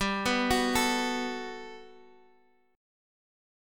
Listen to GM9 strummed